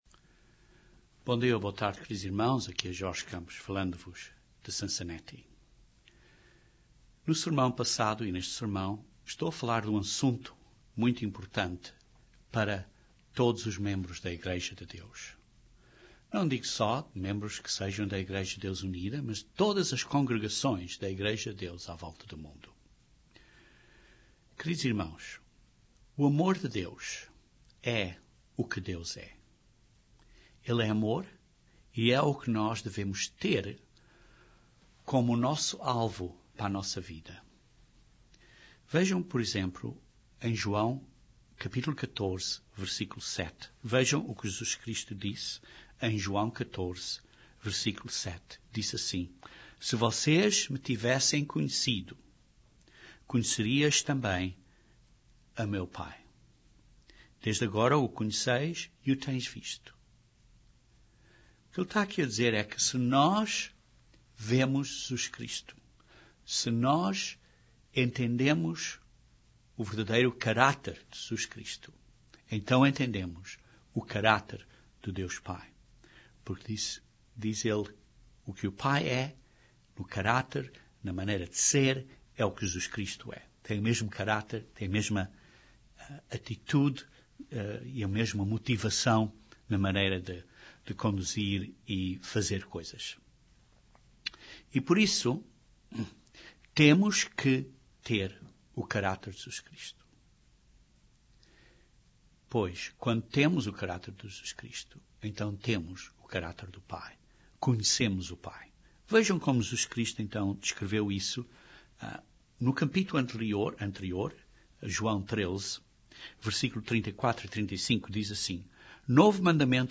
Este sermão, o segundo nesta série, continua a explicação deste tema que é muito importante, para todos os membros da Igreja de Deus, seja onde quer que estejam.